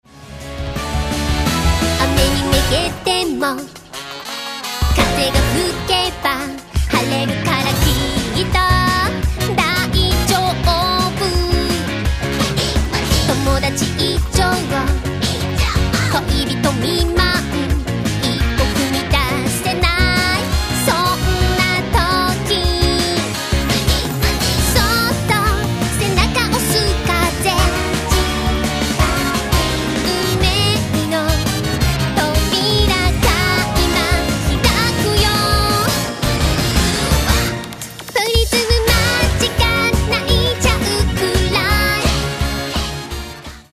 明るくて楽しいオープニングテーマをお楽しみください。
まるでスーパーヒロインタイムみたいなノリノリの楽曲だ。